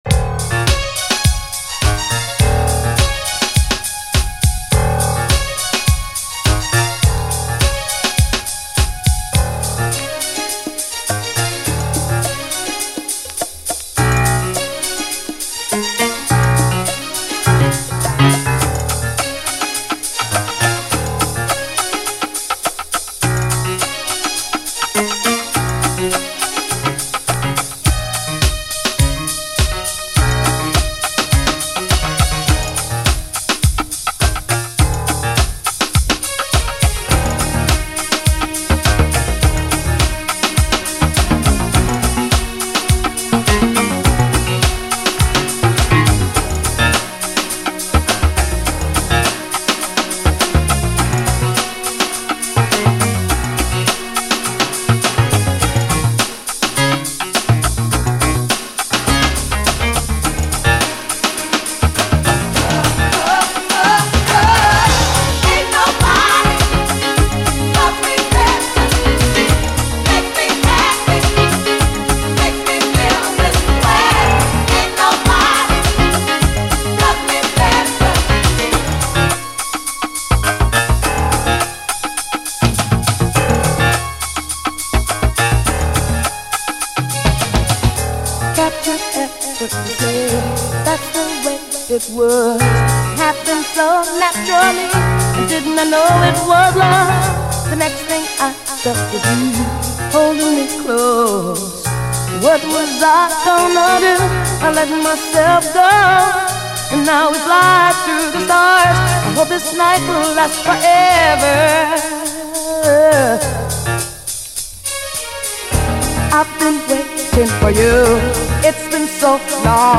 DISCO, DANCE, 7INCH
ピアノやストリングスを際立たせた